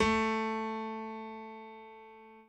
b_pianochord_v100l1-2o4a.ogg